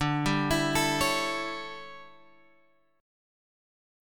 D Major 7th Suspended 2nd Suspended 4th